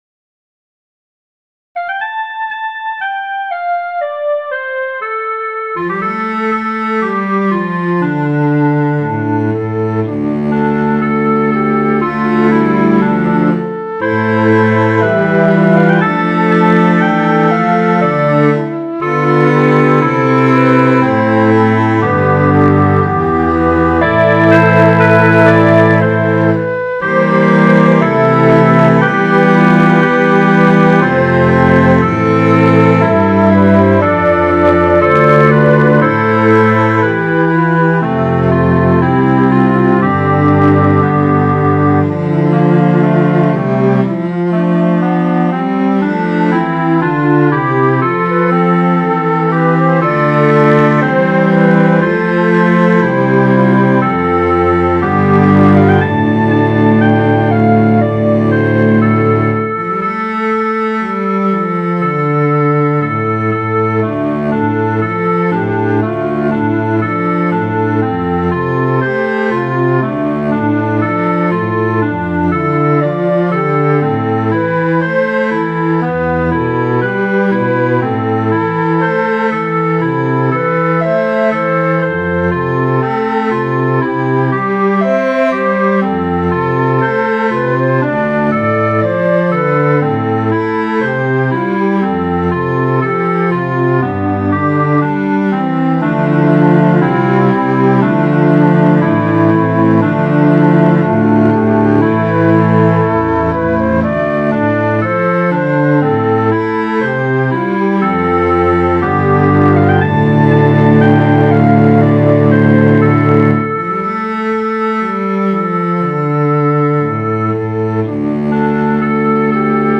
Midi File, Lyrics and Information to A Woman's Work is Never Done